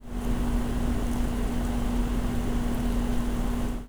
To have a little fun with our demonstration device, we modeled it on an outdoor air conditioner compressor unit and gave it sound effects.
4. Copy three sound effect files by right-clicking each of these links and saving to any convenient temporary location on your PC:
ac-run.wav